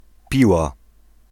Piła (Polish: [ˈpiwa]
Pl-Piła.ogg.mp3